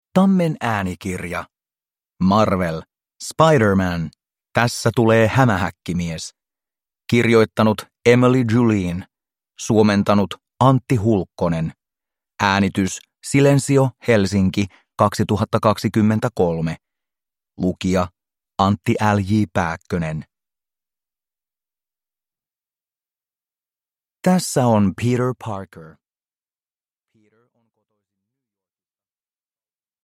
Tässä tulee Hämähäkkimies – Ljudbok – Laddas ner